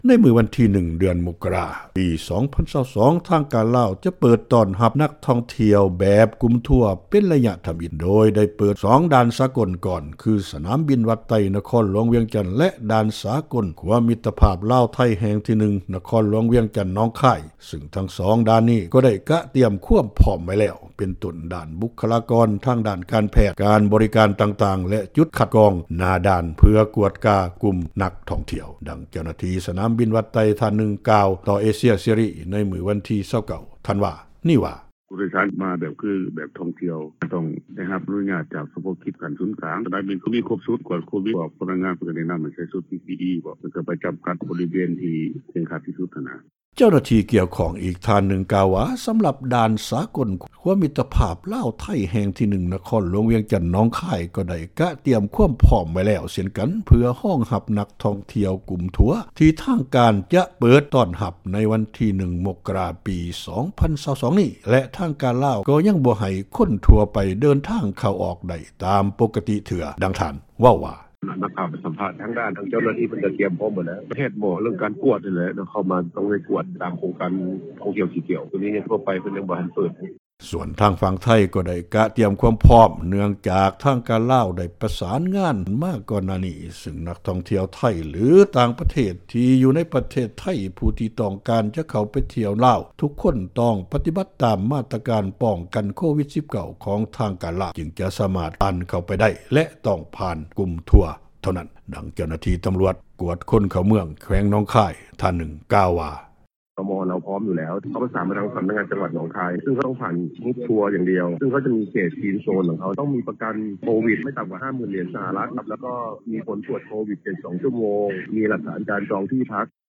ດັ່ງເຈົ້າໜ້າທີ່ສນາມບິນວັດໄຕ ທ່່ານນຶ່ງກ່າວ ຕໍ່ເອເຊັຽເສຣີ ໃນວັນທີ 29 ທັນວາ ປີ 2021 ນີ້ວ່າ:
ຊາວລາວອີກຄົນນຶ່ງກ່າວວ່າ ການເປີດປະເທດຂອງທາງການລາວ ເພື່ອຕ້ອນຮັບນັກທ່ອງທ່ຽວກຸ່ມທົວ ກໍເປັນການດີ ແຕ່ປະມານ 20% ຍັງຮູ້ສຶກຢ້ານຢູ່ ຕ້ອງໄດ້ປ້ອງກັນໂຕເອງຫລາຍຂື້ນ.